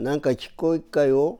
Aizu Dialect Database
Type: Yes/no question
Final intonation: Rising
Location: Showamura/昭和村
Sex: Male